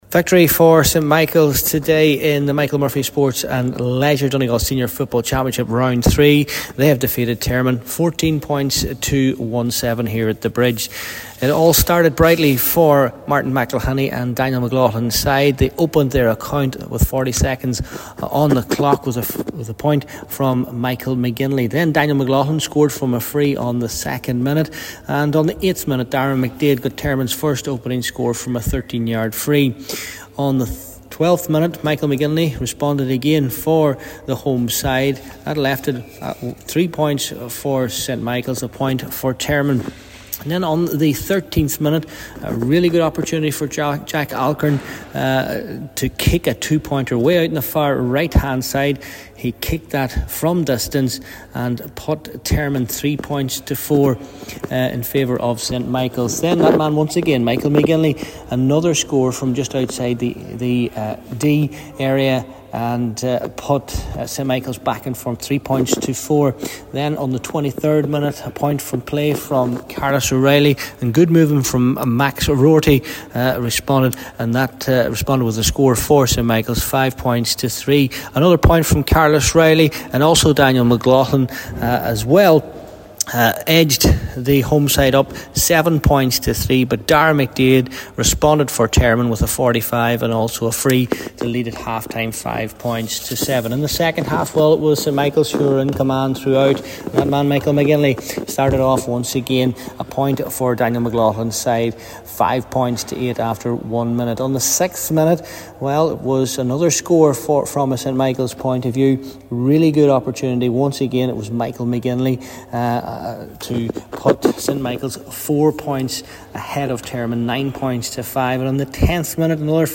reports from The Bridge in Dunfanaghy…